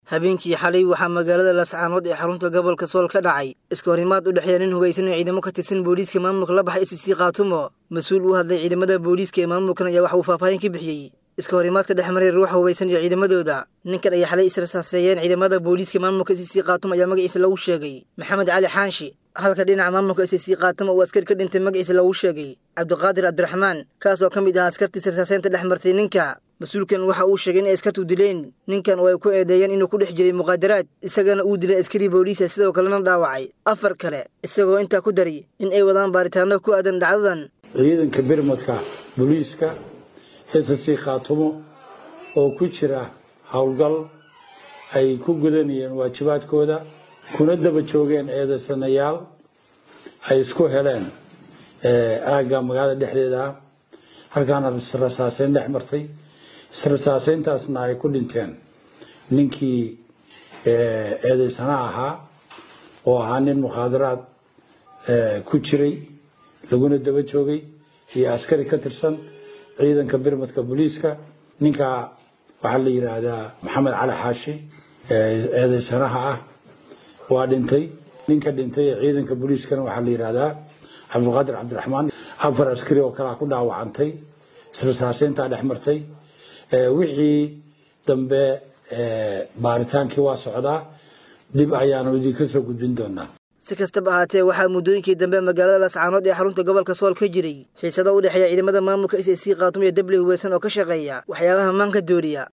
Masuul u hadlay ciidamada Boliska ee maamulka ayaa waxa uu faahfaahin ka bixiyay, iska hor-imaadka dhex maray ruuxa hubeysan iyo ciidamadooda.